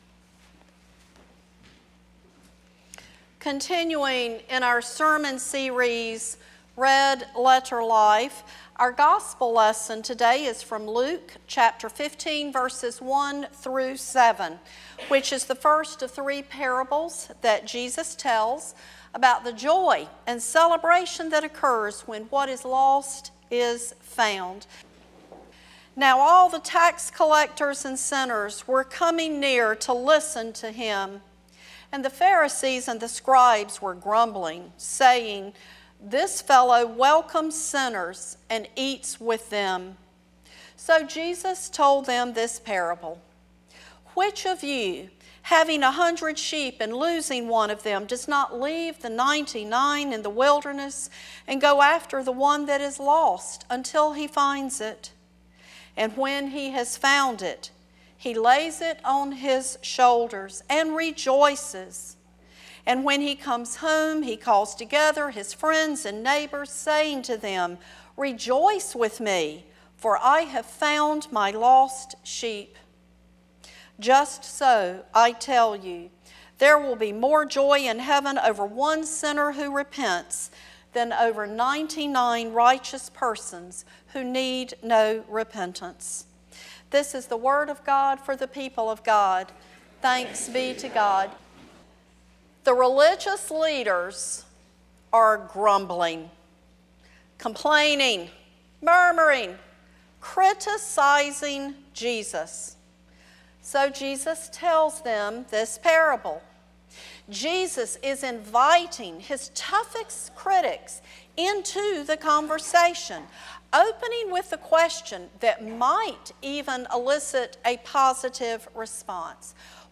First Cary UMC's First Sanctuary Sermon &ndash